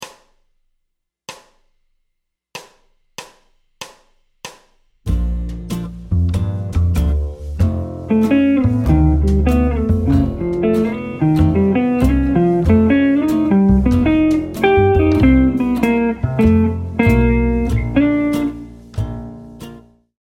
Tonalité du morceau : Bb